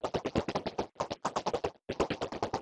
AngryGiantInsect.wav